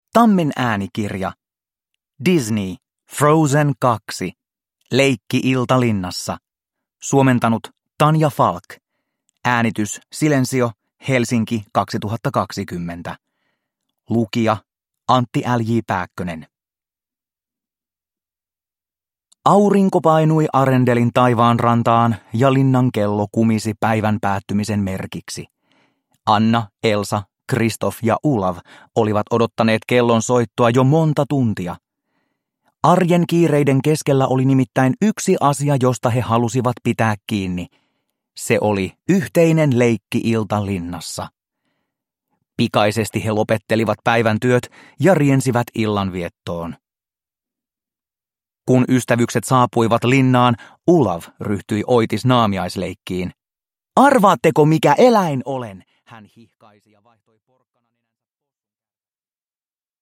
Frozen 2 Leikki-ilta linnassa – Ljudbok – Laddas ner